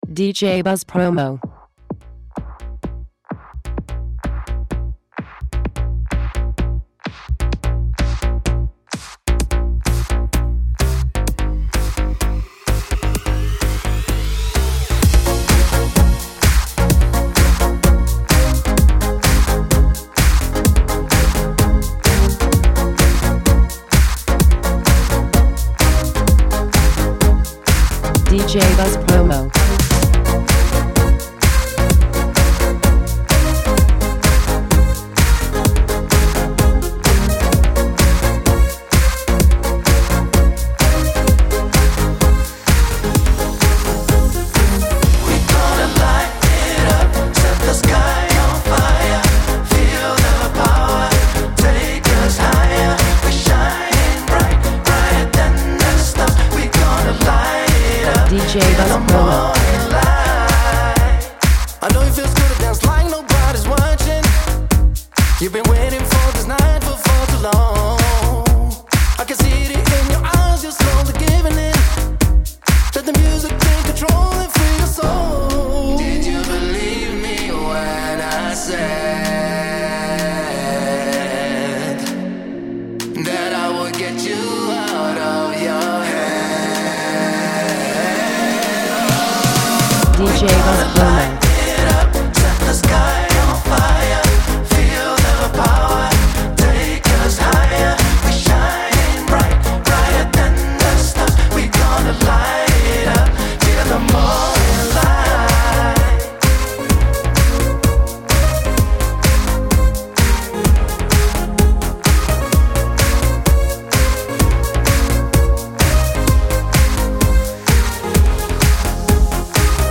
Having become a producer of his own House or EDM tracks
Extended